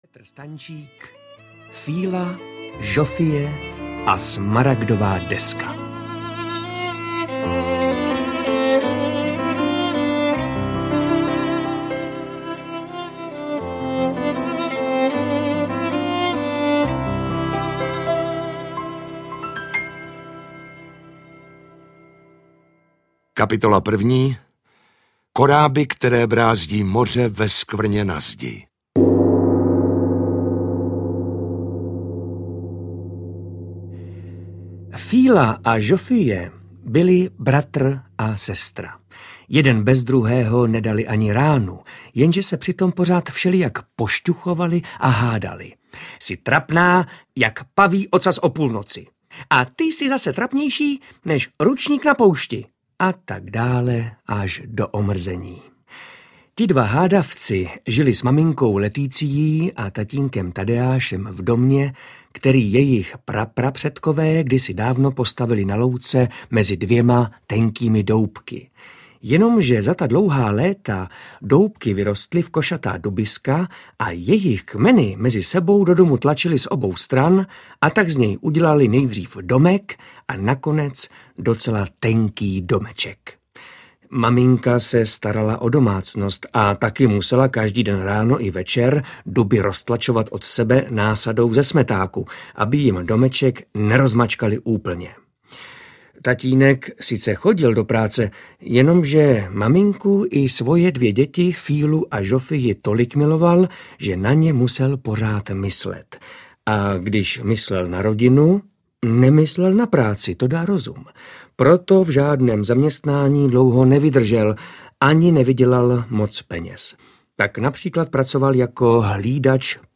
Fíla, Žofie a Smaragdová deska audiokniha
Ukázka z knihy
• InterpretMartin Myšička